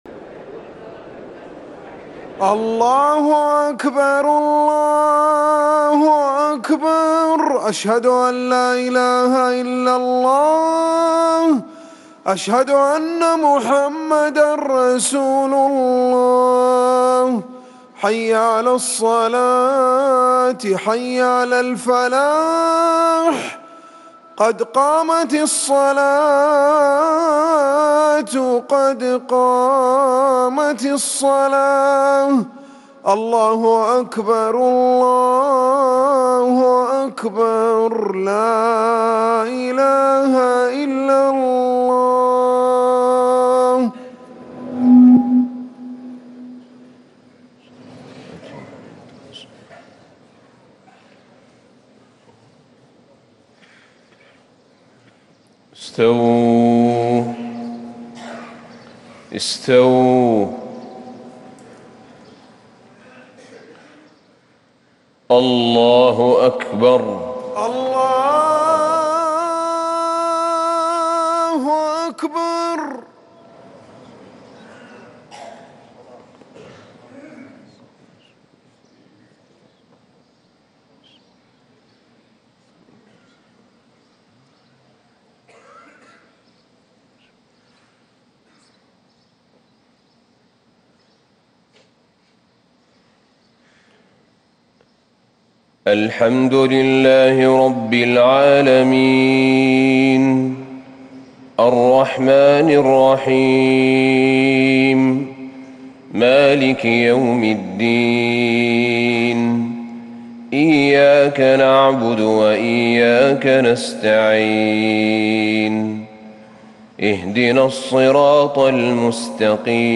صلاة الفجر 1-9-1440هـ سورتي المزمل و الغاشية | fajr 6-5-2019 prayer from surah Al-muzzammil and al-Ghashiyah > 1440 🕌 > الفروض - تلاوات الحرمين